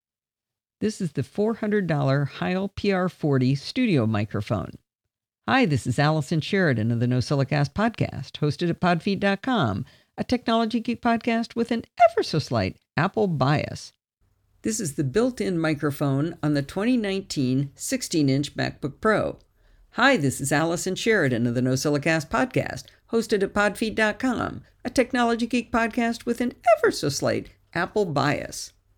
I’ll play you a little audio file here comparing my Heil PR-40 studio mic to the internal mic on the 16″ MacBook Pro.
I know it’s not AS good as the Heil (I’d be bummed if it was) but if you get this Mac and want to do a recording for the show using the internal mic, it is well up to the task of producing a good sound.
heil-vs-1622-mbp-recording.m4a